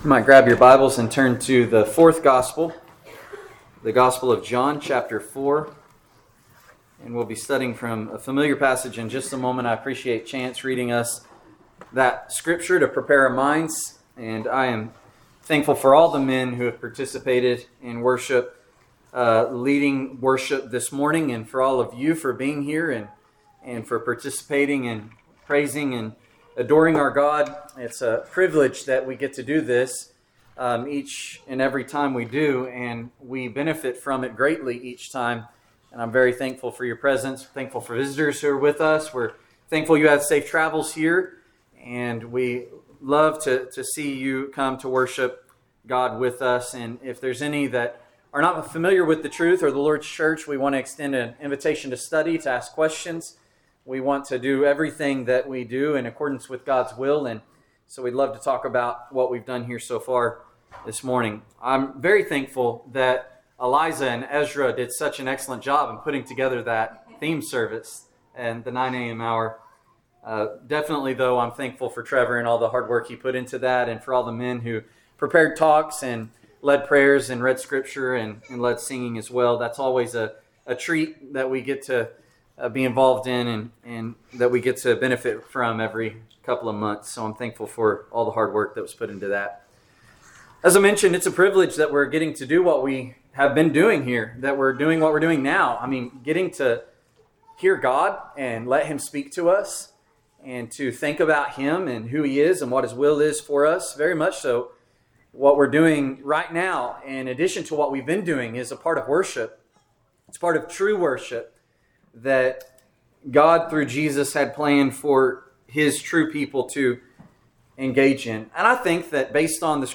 Sermon: True Worshipers